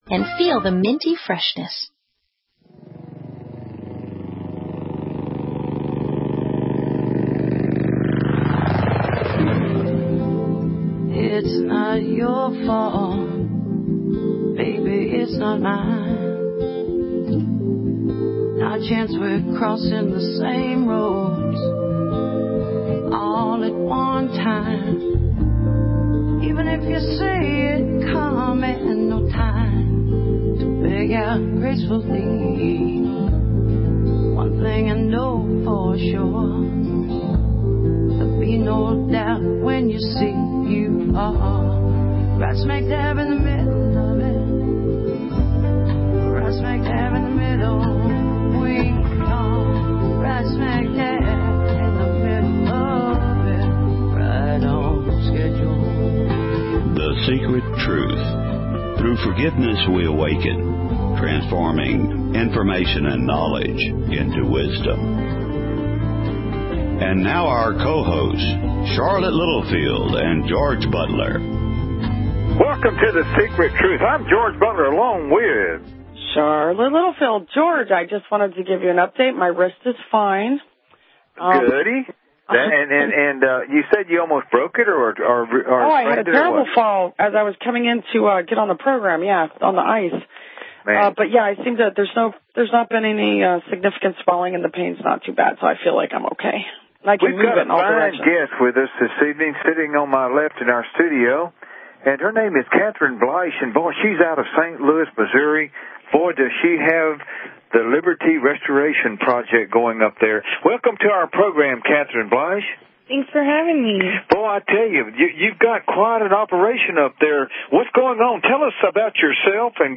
interviews
live in studio